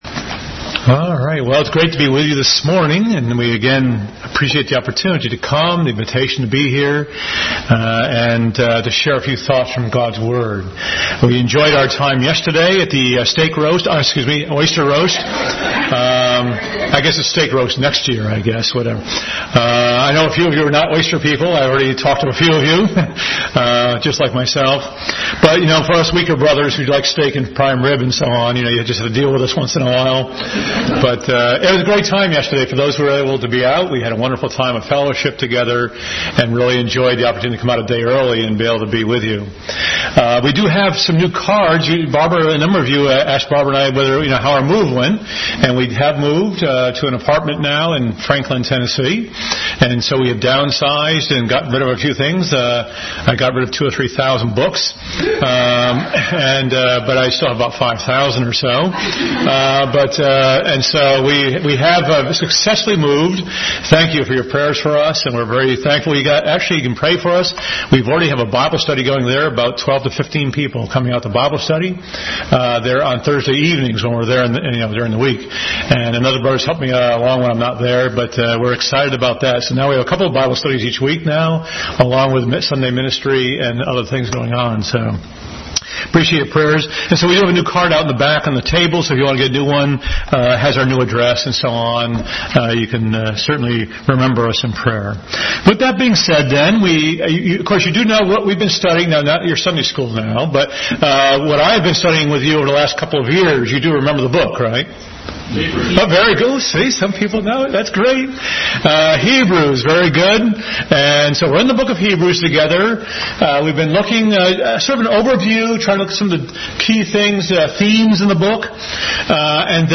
Passage: Hebrews 10:1-18, Psalm 110 Service Type: Sunday School